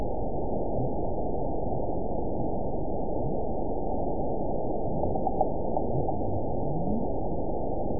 event 912628 date 03/30/22 time 13:29:58 GMT (3 years, 1 month ago) score 9.04 location TSS-AB05 detected by nrw target species NRW annotations +NRW Spectrogram: Frequency (kHz) vs. Time (s) audio not available .wav